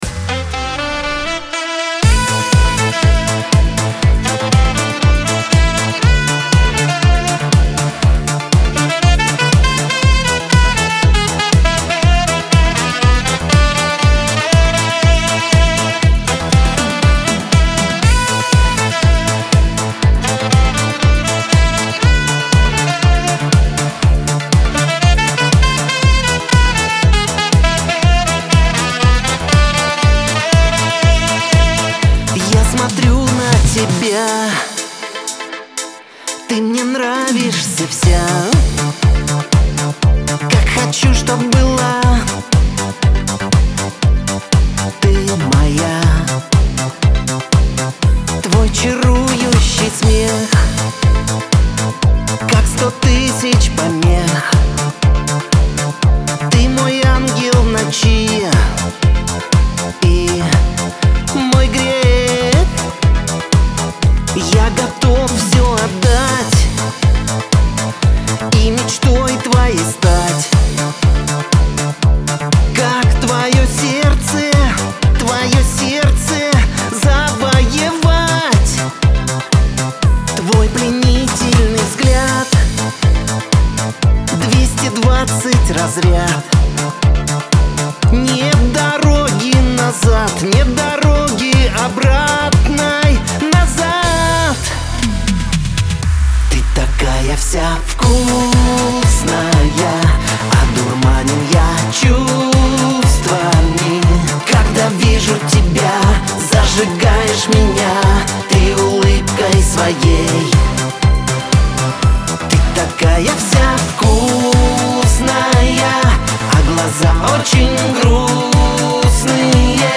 дуэт , диско
эстрада , pop